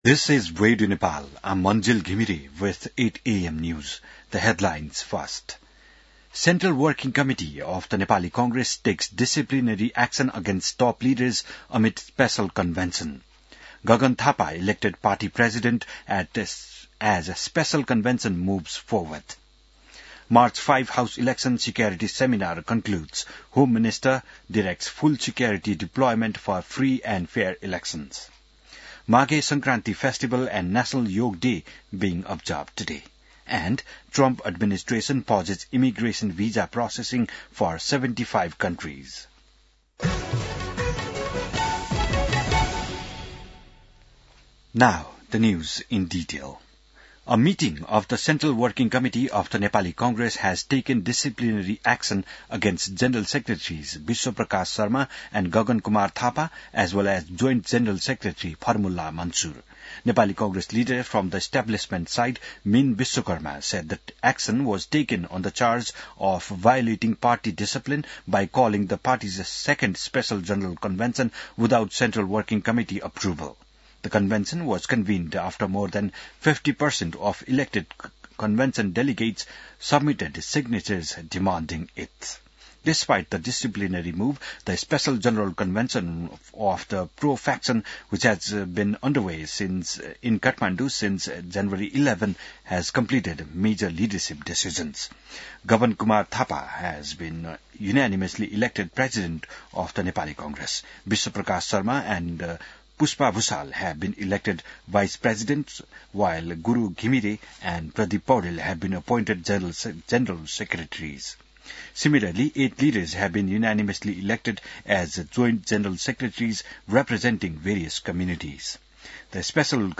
बिहान ८ बजेको अङ्ग्रेजी समाचार : १ माघ , २०८२